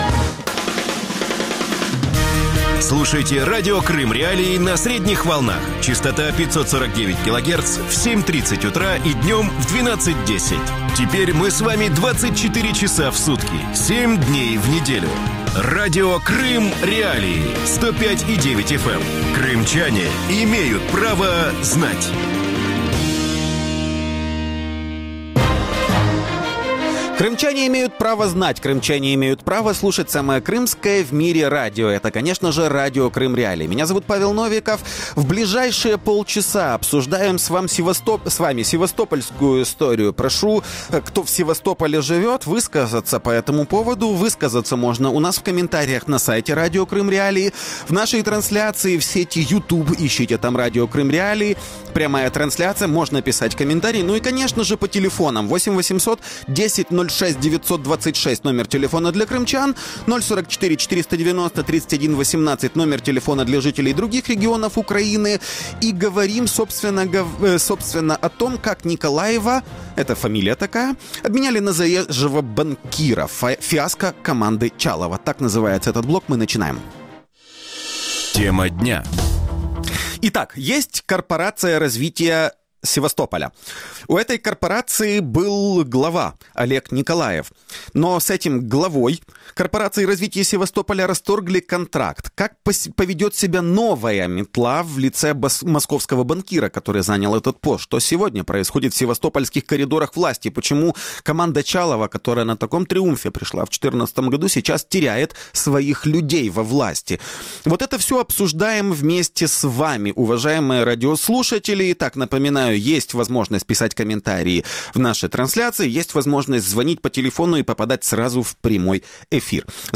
журналист из Севастополя
севастопольский активист; Василий Зеленчук, экс-депутат Севастопольского городского совета.